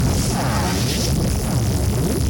laser-beam-01.ogg